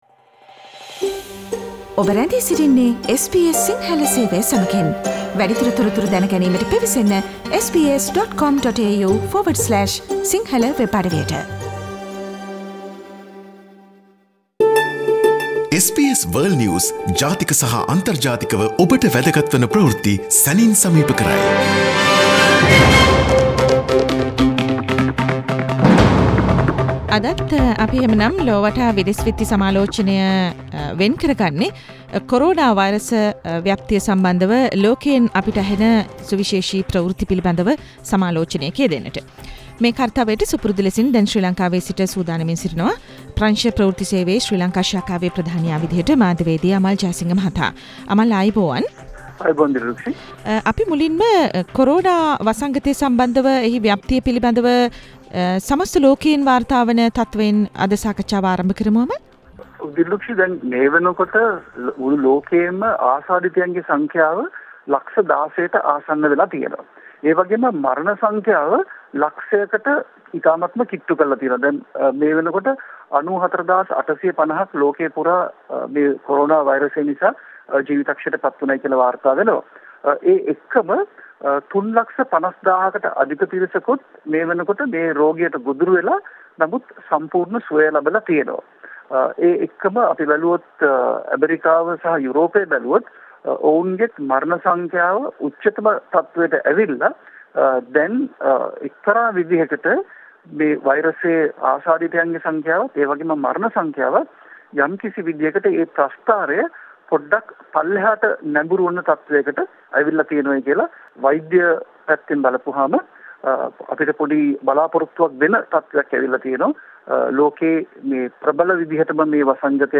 weekly world news wrap Source: SBS Sinhala radio